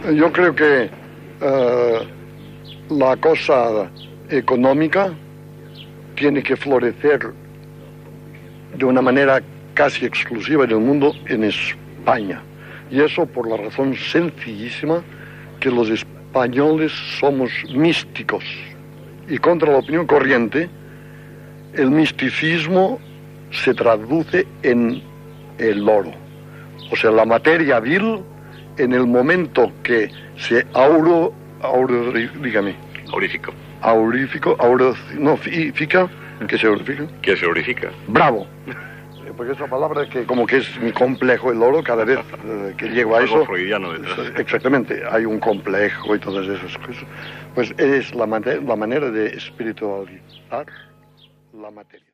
Programa realitzat des de la casa de Salvador Daí a Port Lligat.
Informatiu